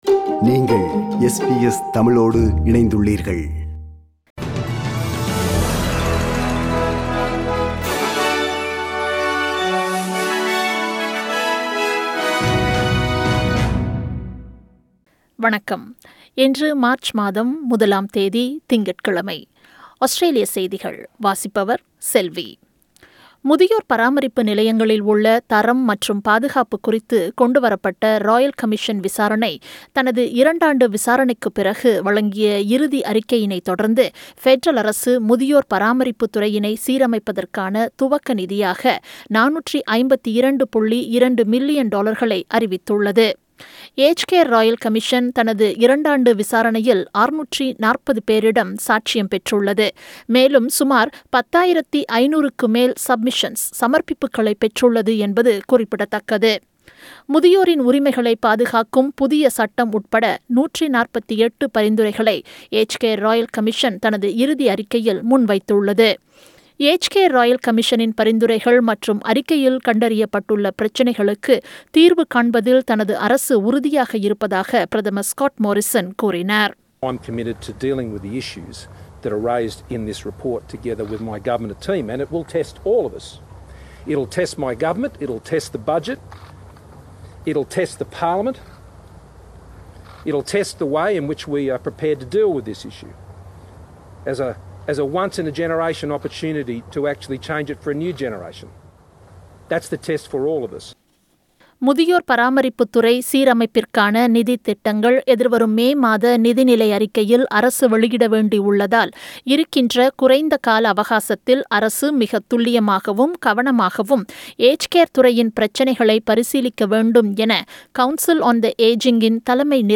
Australian news bulletin for Monday 01 March 2021.